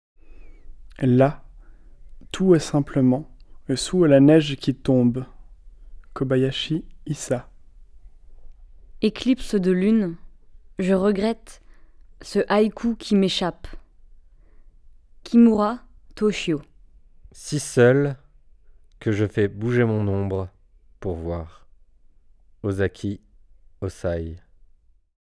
HAÏKUS